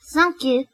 描述：我是美国人，非常不会说日语。
对不起任何发音问题。
Tag: 说话 声音 女孩 女性 声带 日本